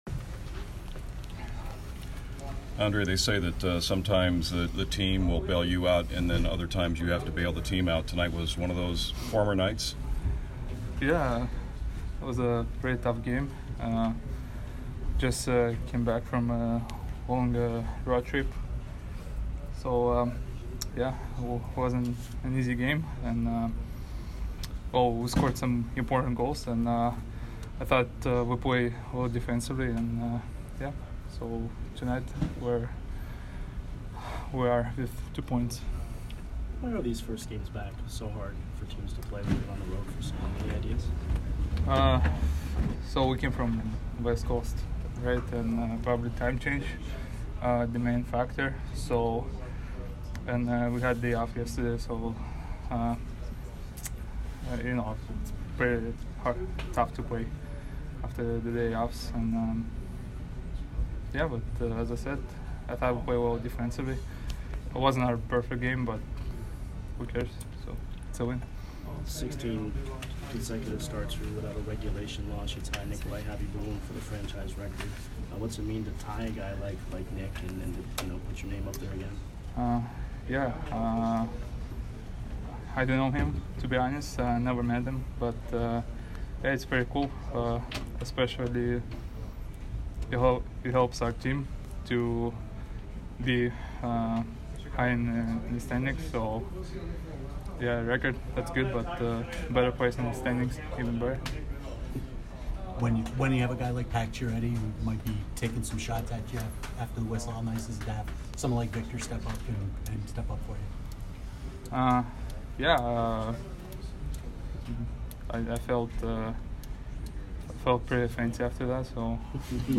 Vasilevskiy post-game 2/4